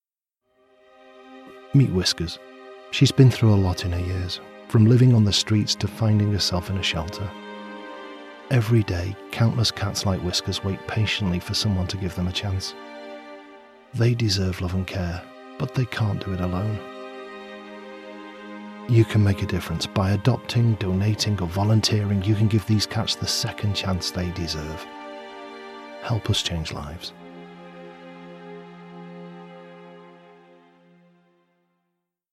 Radio Ad - Cat Charity
English - United Kingdom